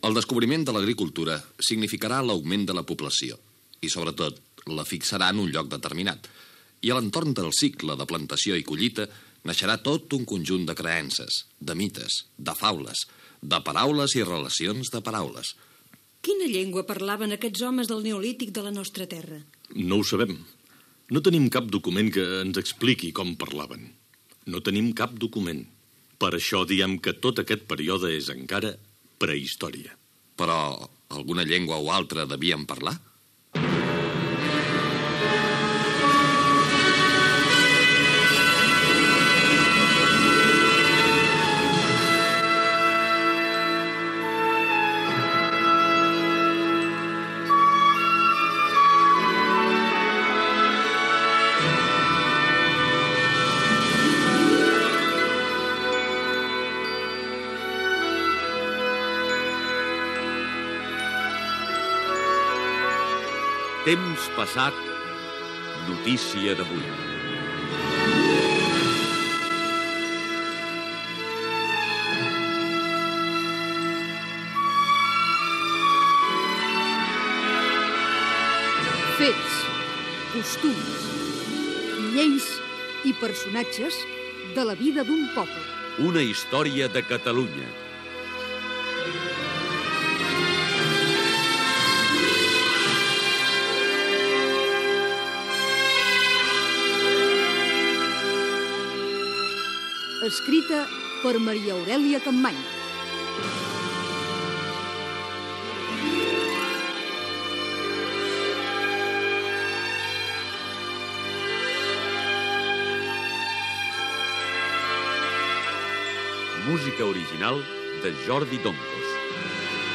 165fcf6ca4a67c4f61d9f7ba40b7b8eeeb770d8f.mp3 Títol Ràdio 4 Emissora Ràdio 4 Cadena RNE Titularitat Pública estatal Nom programa Temps passat notícia d'avui Descripció Careta i inici del capítol primer "Enlloc de la història".